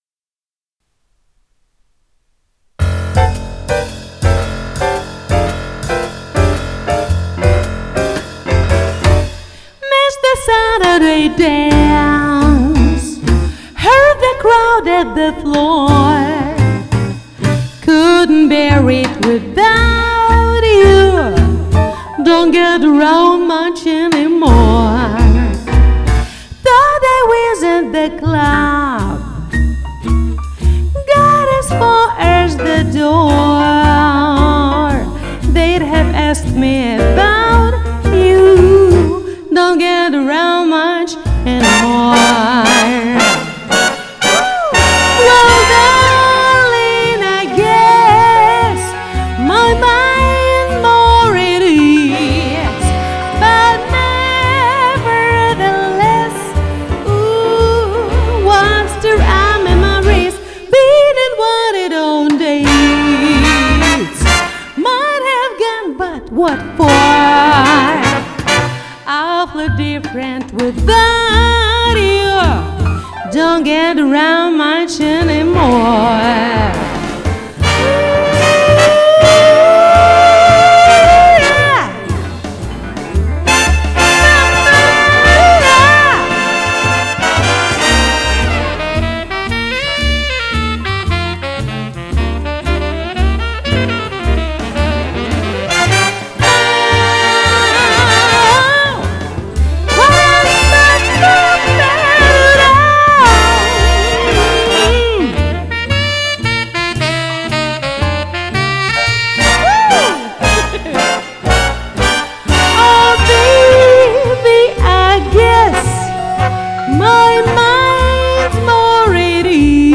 Комментарий инициатора: Что-нибудь из джаза.